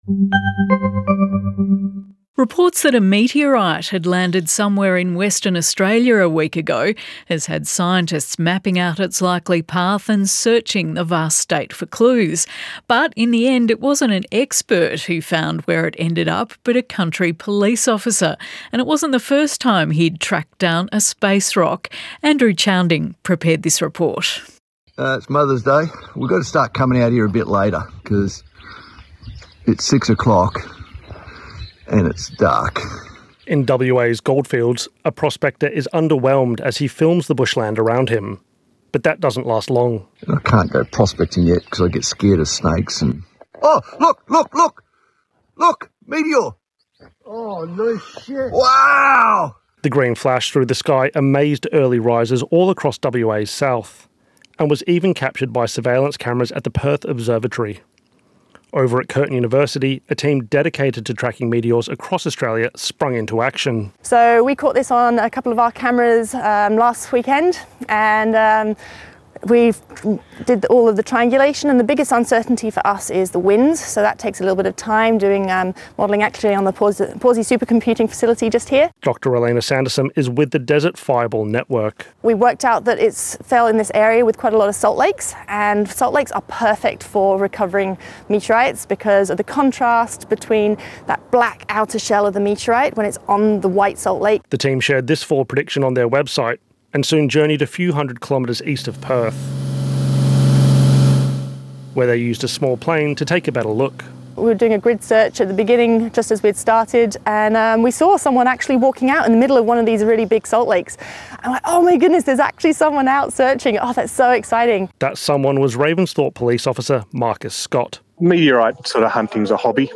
Interview
WAbolideradioreport.mp3